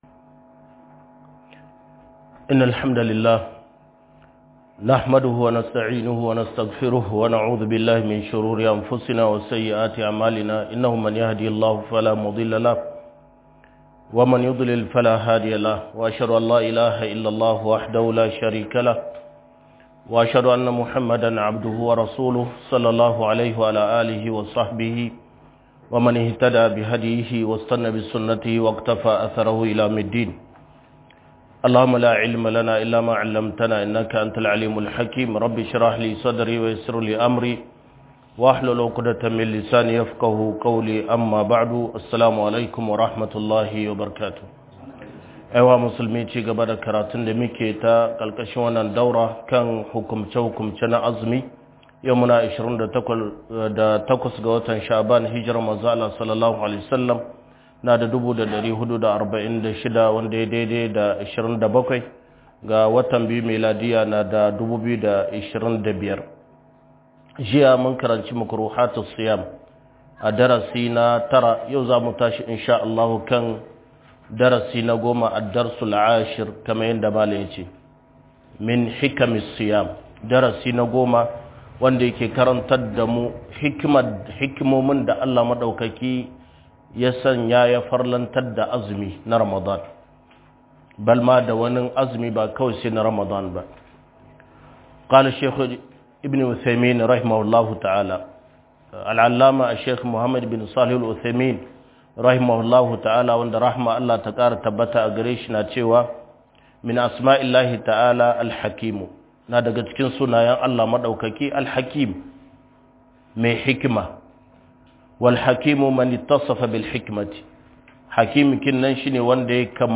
مذكرة في أحكام الصيام درس ٩